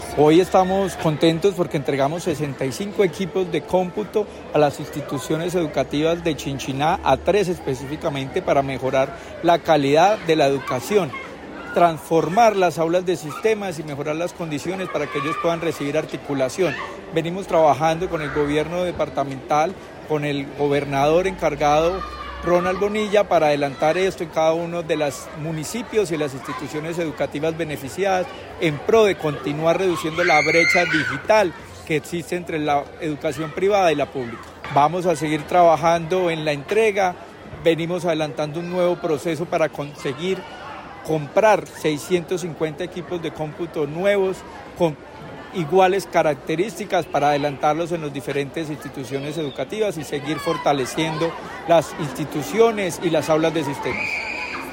El acto de entrega se realizó en compañía de la comunidad de la Institución Educativa San Francisco de Paula.
Secretario de Educación de Caldas, Luis Herney Vargas Barrera.